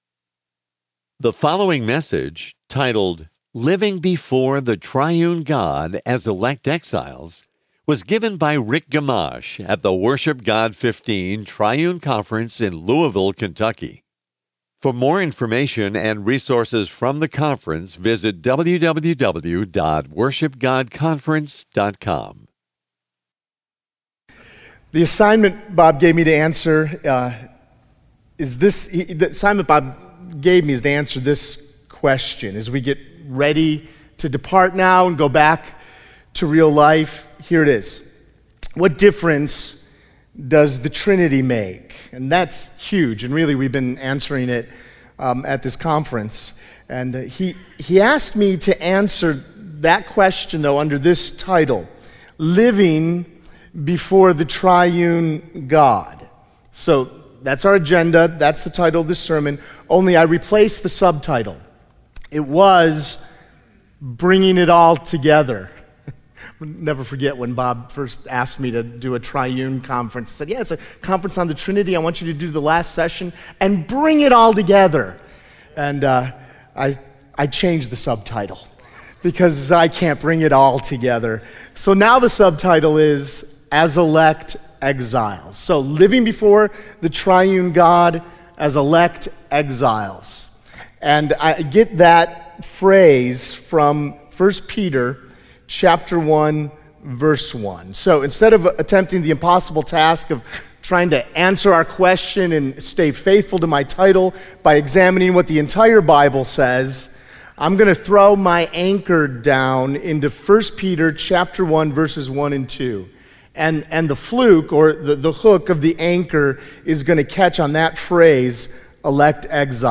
Conference Messages